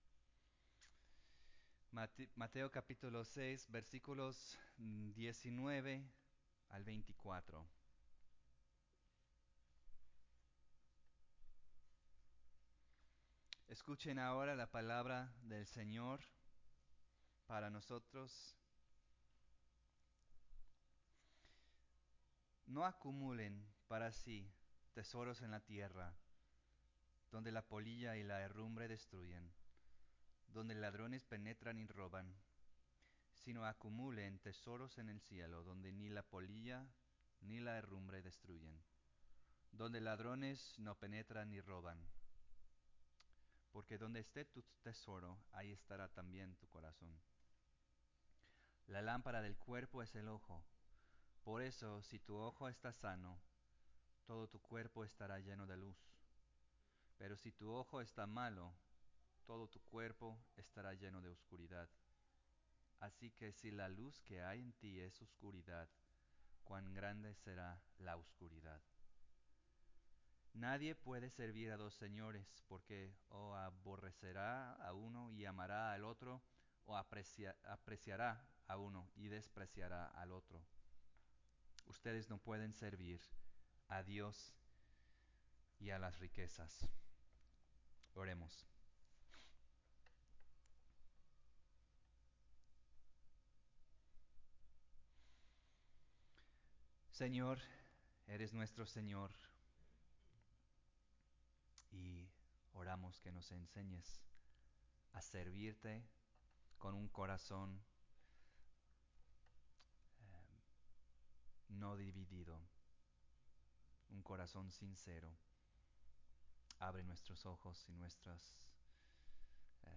Sermon-del-Monte-11.mp3